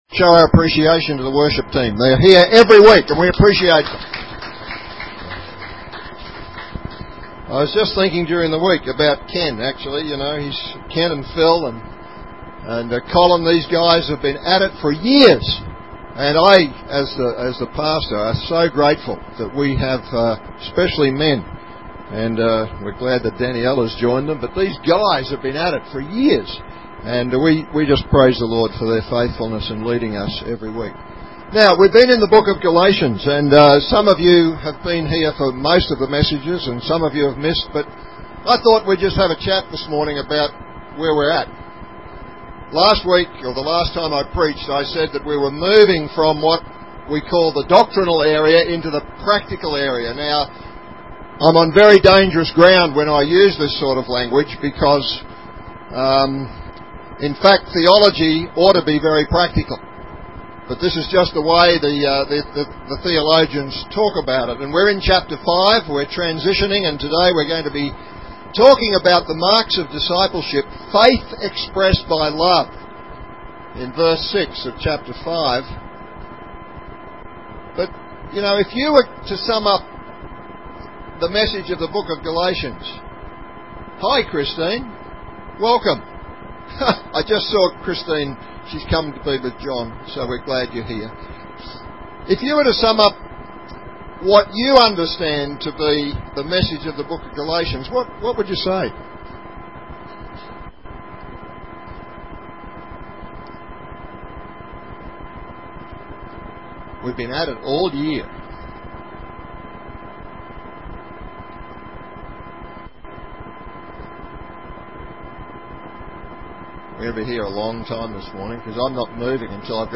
Categories Sermon Tags galatians